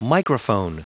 Prononciation du mot microphone en anglais (fichier audio)
Prononciation du mot : microphone